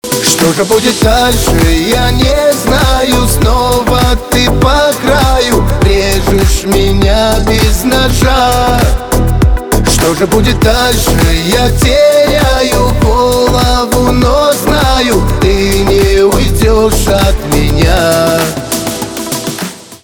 кавказские
битовые